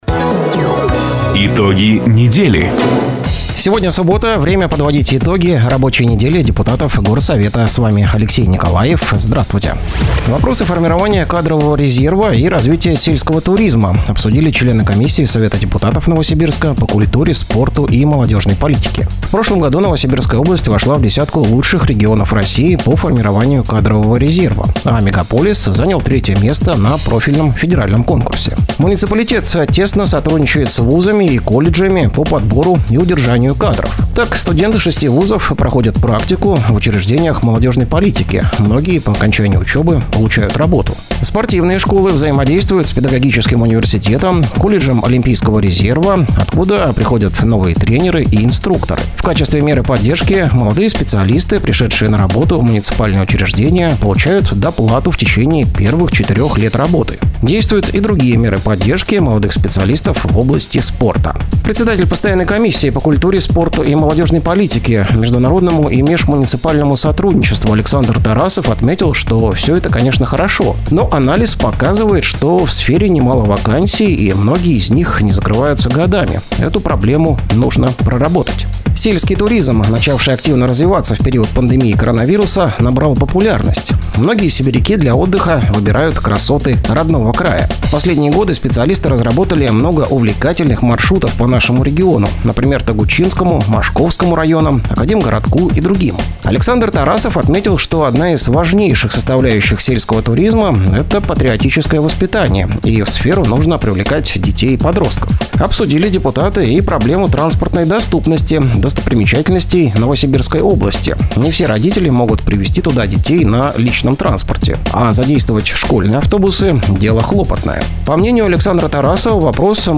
Запись программы "Итоги недели", транслированной радио "Дача" 17 мая 2025 года